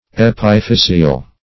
Epiphyseal \Ep`i*phys"e*al\